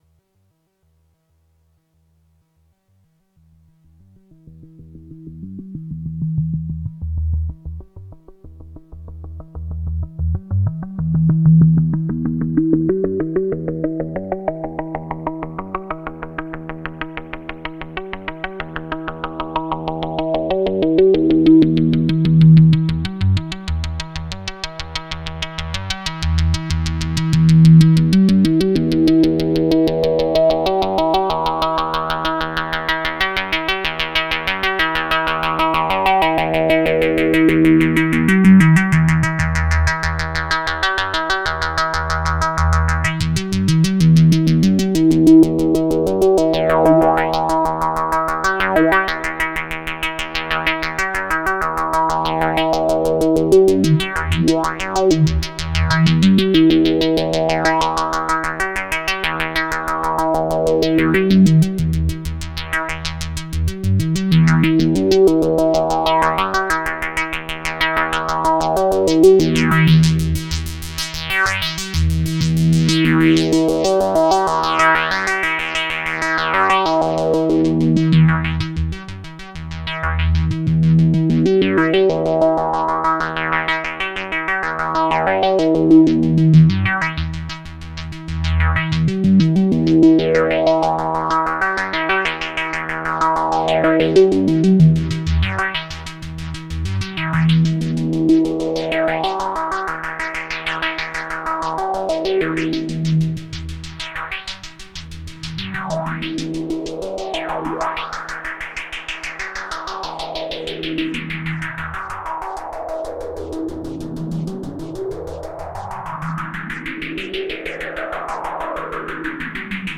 sounds glorious!